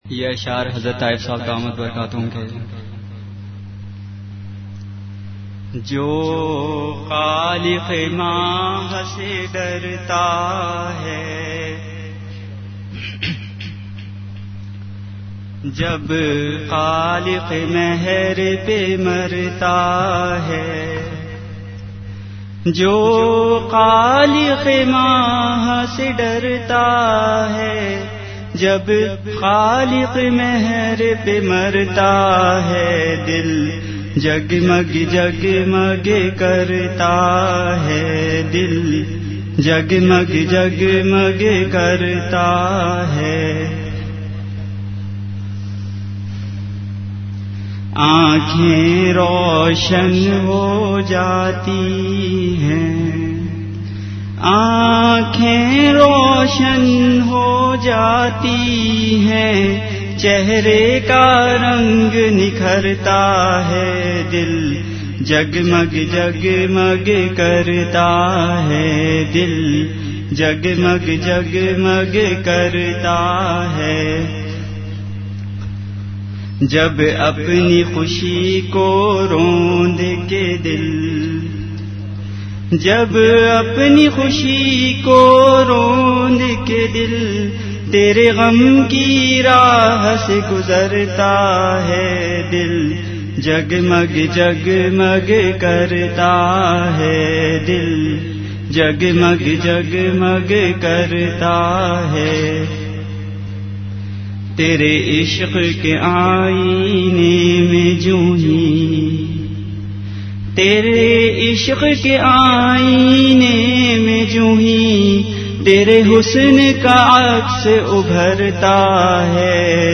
Delivered at Home.
Majlis-e-Zikr · Home Allah(swt